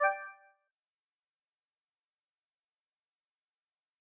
coin1.ogg